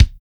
TITE HARD K.wav